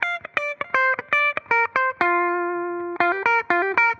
Index of /musicradar/dusty-funk-samples/Guitar/120bpm
DF_BPupTele_120-G.wav